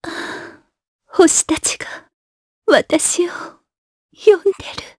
Yuria-Vox_Dead_jp.wav